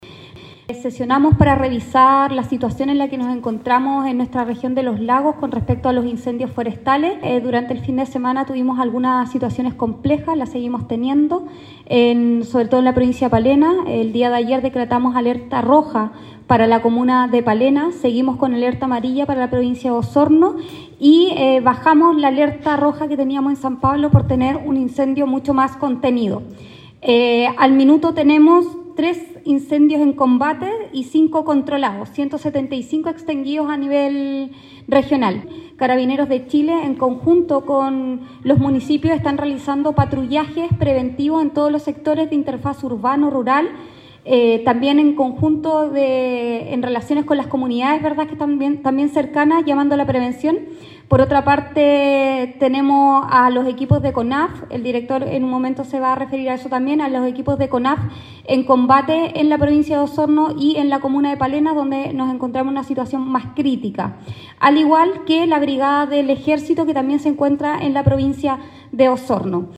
Con motivo de esta emergencia, este lunes se realizó un comité para la gestión de riesgos y desastres región de Los Lagos, encabezado por la delegada presidencial regional Giovanna Moreira, quien remarcó que se trata de una situación compleja la que tiene lugar en Palena, por lo que se han dispuesto recursos como el helicóptero para el combate a este siniestro forestal.